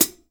Index of /90_sSampleCDs/AKAI S6000 CD-ROM - Volume 3/Hi-Hat/12INCH_LIGHT_HI_HAT